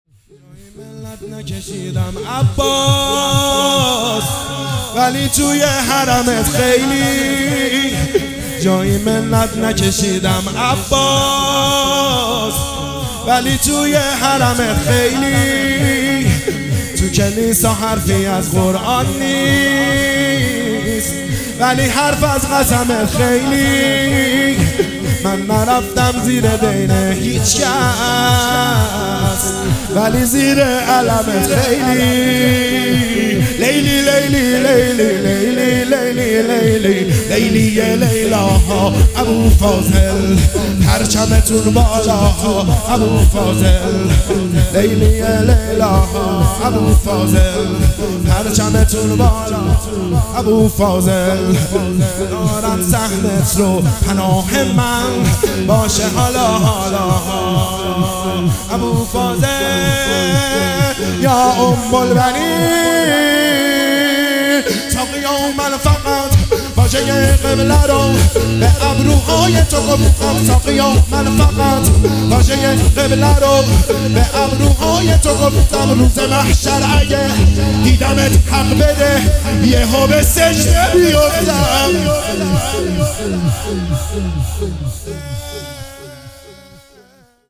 شور _ جایی منت نکشیدم عباس
شهادت حضرت رباب (س)1403